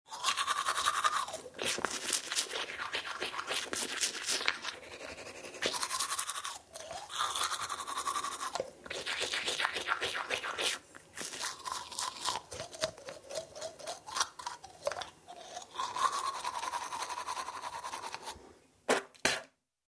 Звук зубной щетки во рту